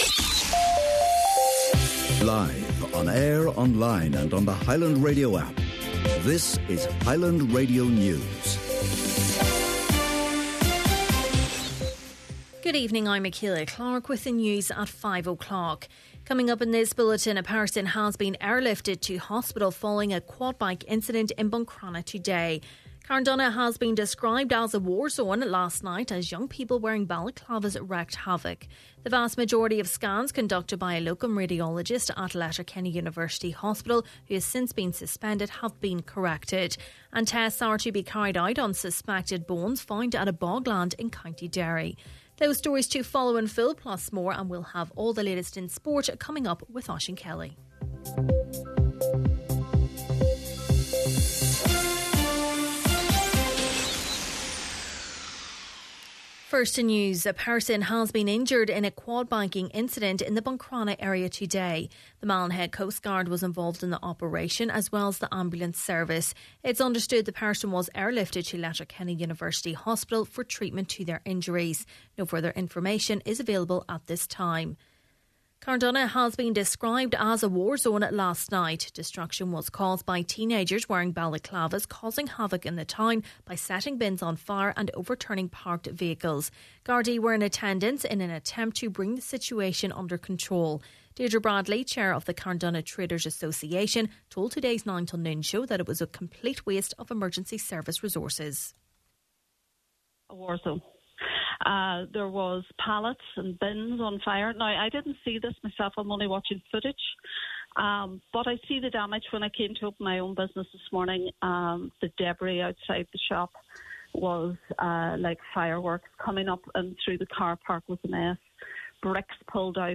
Main Evening News, Sport and Obituaries – Wednesday November 1st